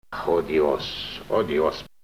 voce masc (afectat) - Odios oodios....